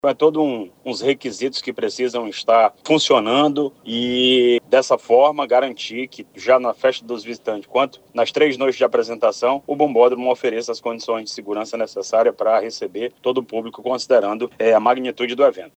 SONORA-2-Orleison-Muniz.mp3